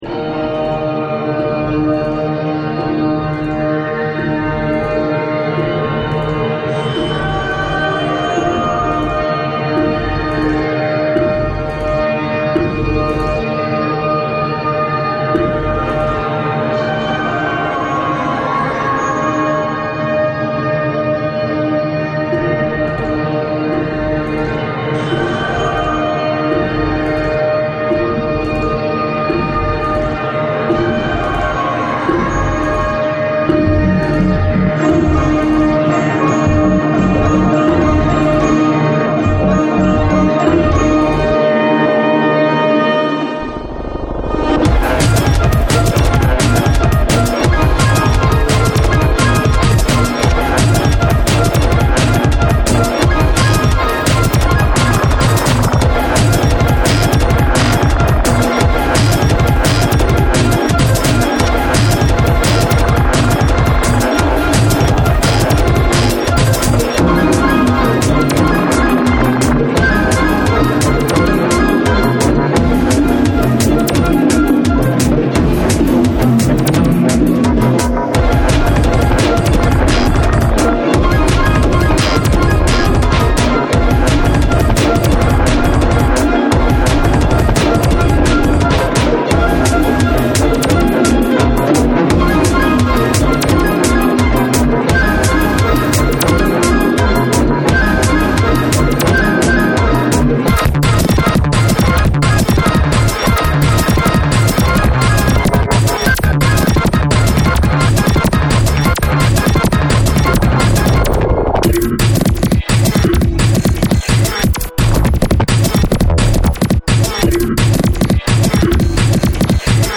Drum'n Bass